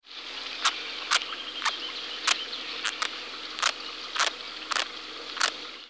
Release Calls
Sound  This is a 5 second recording of the release calls of a frog on land, recorded during daylight. Birds and flowing water are heard in the background.